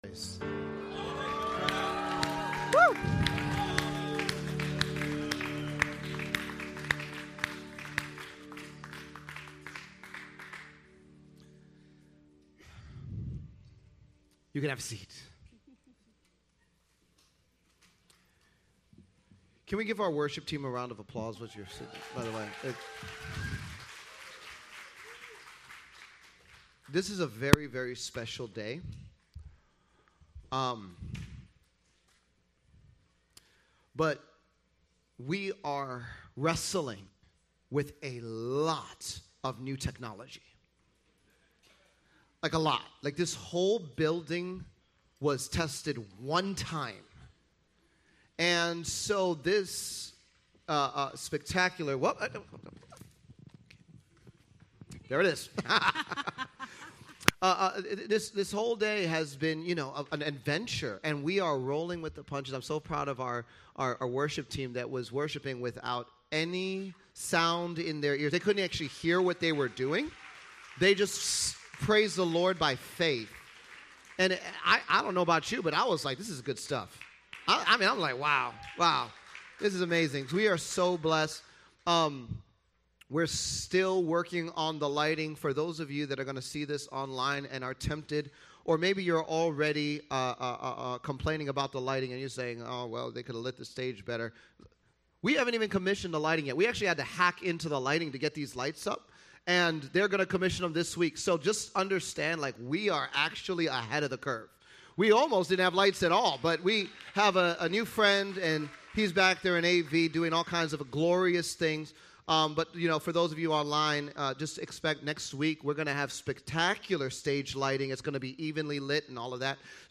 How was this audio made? We welcome you to the GRAND OPENING of the new church building today